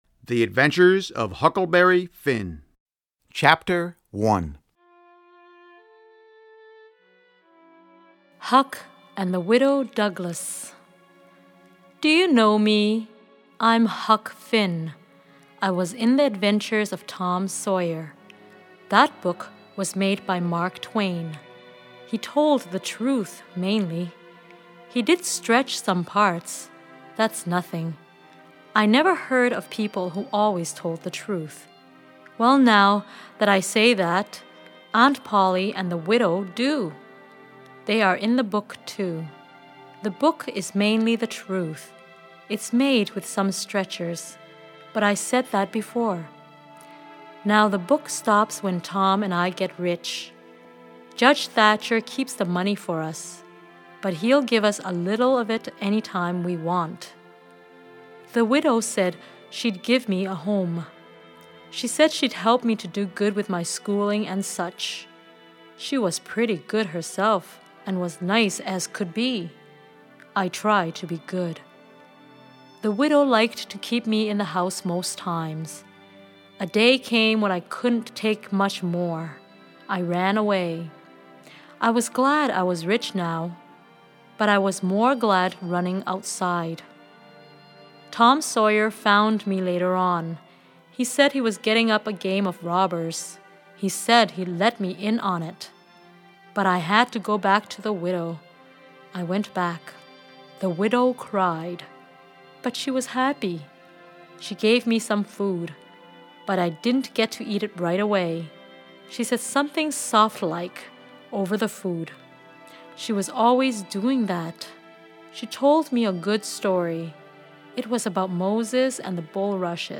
These high-interest read-alongs have been adapted into 10 short chapters aimed at introducing students to great classic literature, while improving comprehension, vocabulary and fluency. Expertly paced narration provides sound effects to keep interest high, while students follow along, and then pause to work on activities at the end of each chapter.
Each audio CD: Includes a word-for-word reading directly from the chapter pages in the book broken into 10 chapters with exciting sound effects.